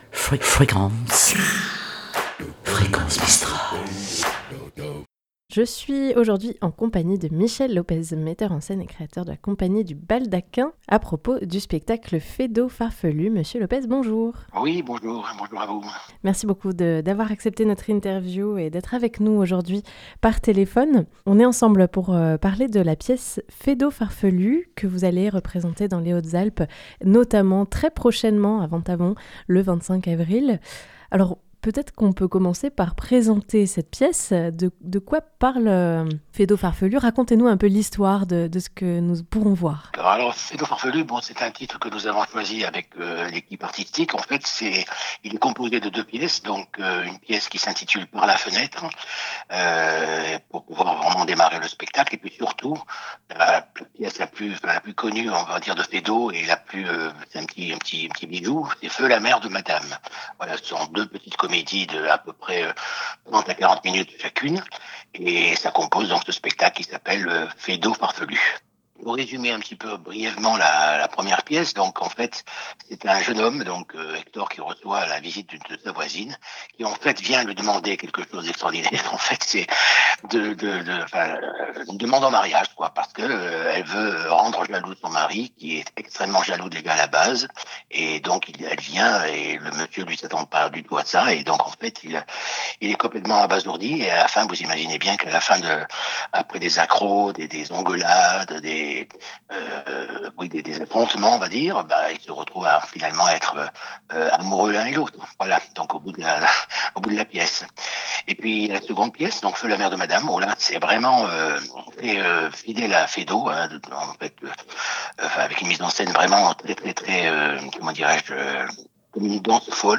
260416 - ITW pour Feydeau Farfelu.mp3 (36.76 Mo)